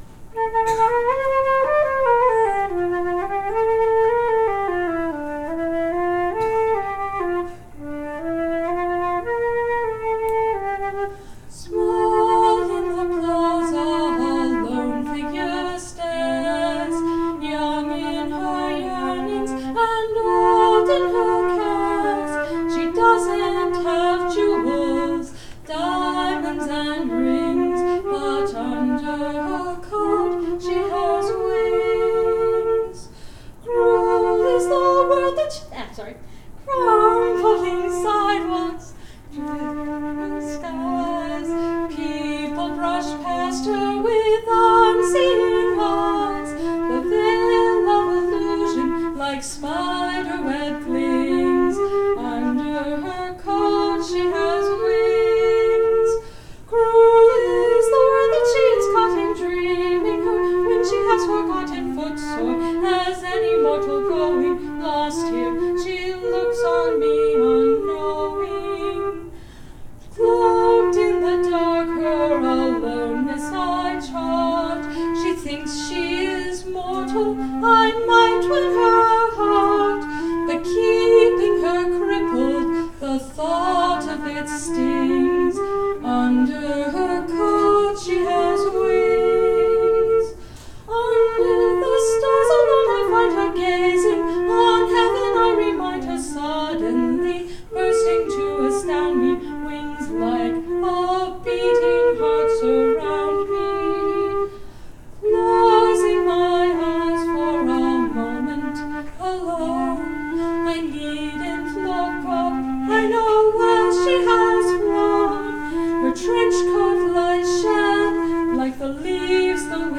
This concert was performed at Consonance 2007